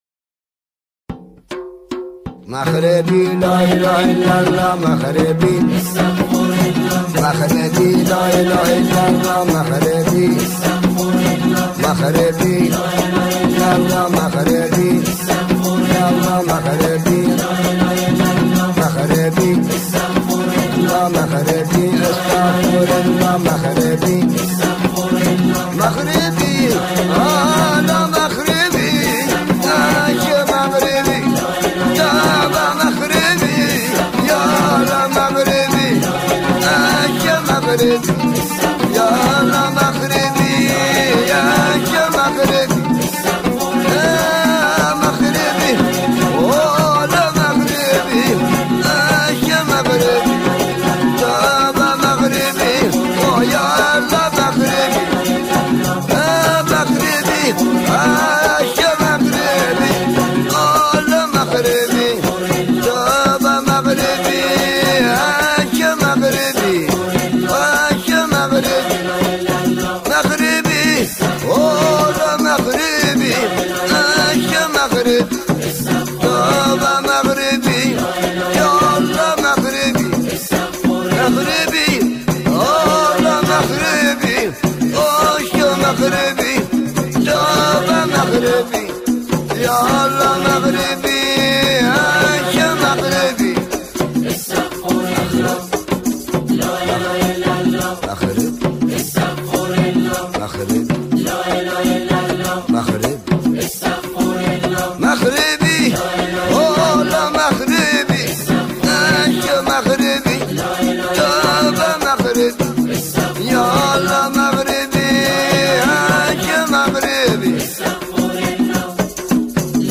آن‌ها شعری را با گویش جنوبی اجرا می‌کنند.